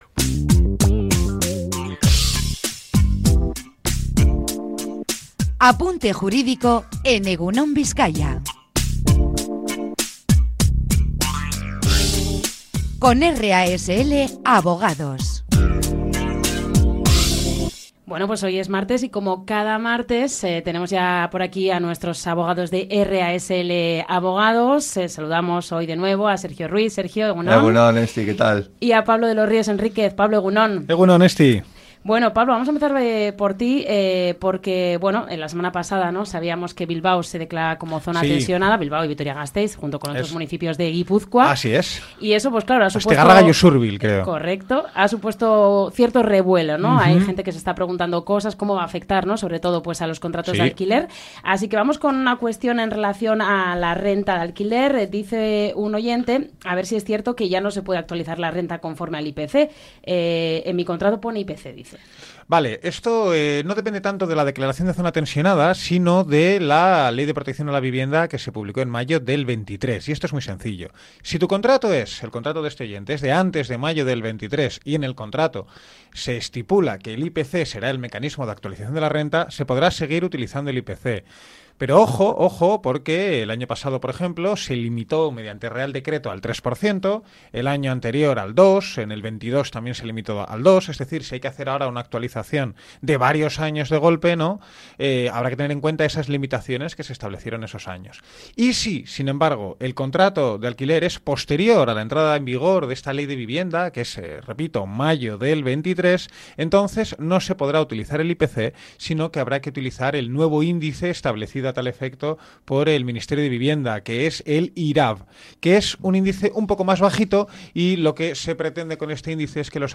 responden a las dudas de la audiencia sobre la declaración de Bilbao como zonas tensionada y cómo esto va a afectar a la renta de los alquileres. Además, se resuelven algunas cuestiones relacionadas con herencias y testamentos.